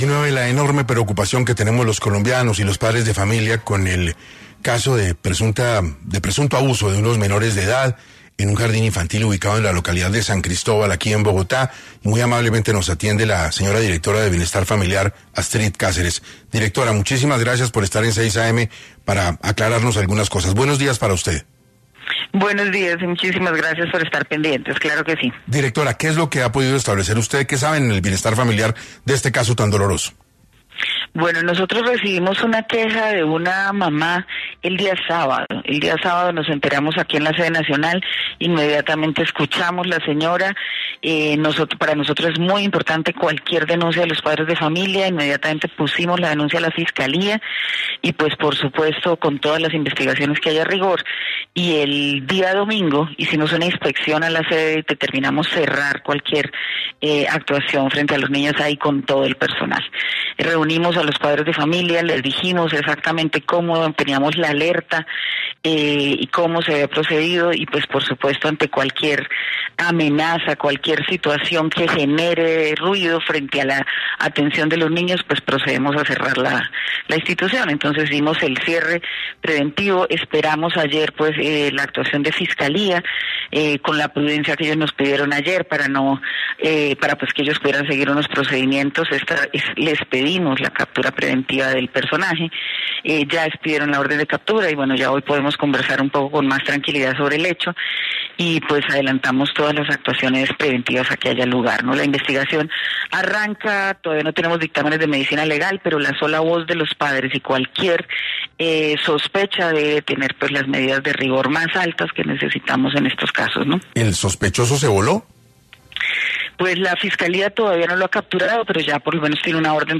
Astrid Cáceres, Directora de Bienestar Familiar, dio detalles a la mesa de trabajo de 6AM sobre los casos de abuso sexual a niños del Jardín parques de Canadá.
Astrid Cáceres explicó en los micrófonos de Caracol Radio cómo está actuando el ICBF (Instituto Colombiano de Bienestar Familiar) frente a los casos de abusos de menores en el Jardín Canadá.